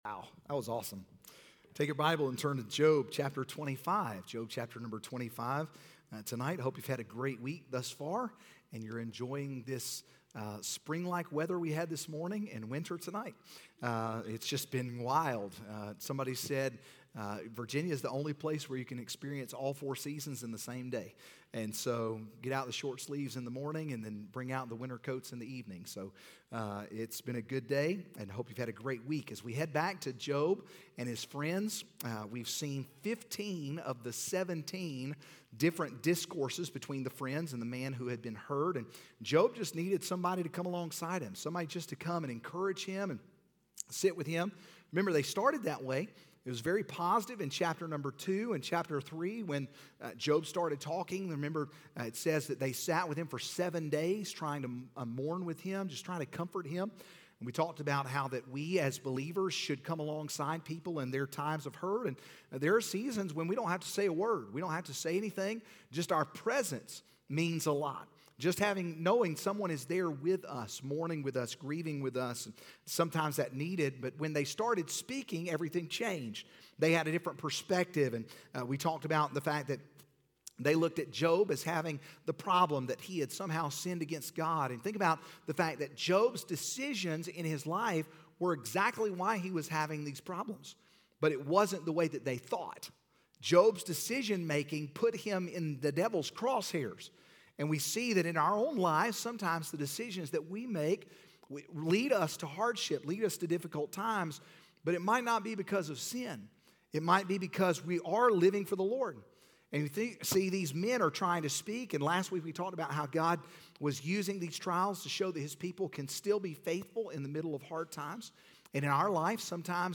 Sermons « » Where Is God?